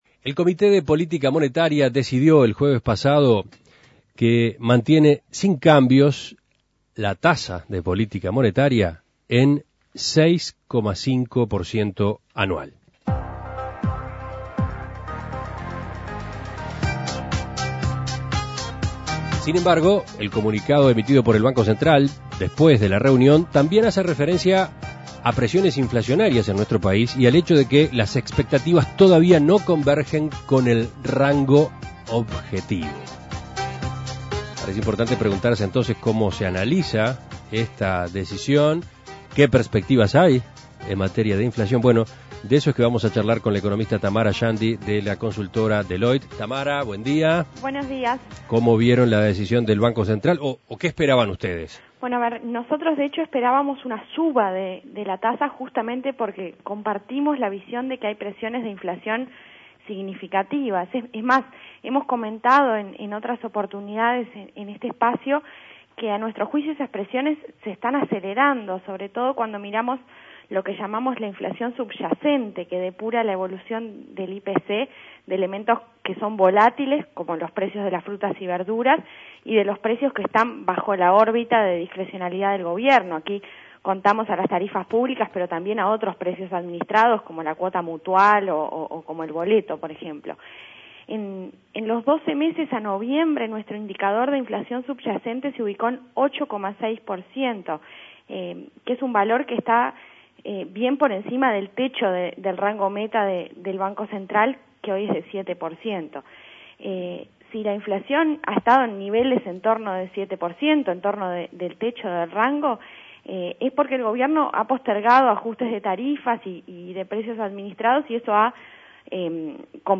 Análisis Económico El Banco Central mantuvo la tasa de política monetaria sin cambios pese a reconocer mayores presiones inflacionarias.